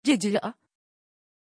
Aussprache von Cecília
pronunciation-cecília-tr.mp3